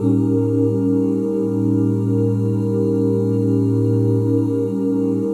OOHA FLAT5.wav